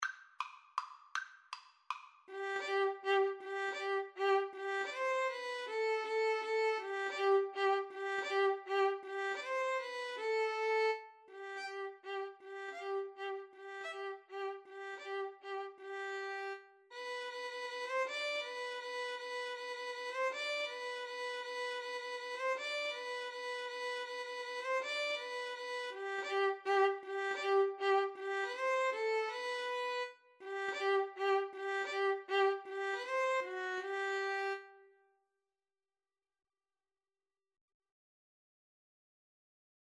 3/4 (View more 3/4 Music)
Classical (View more Classical Violin-Cello Duet Music)